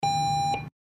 beep_attention.mp3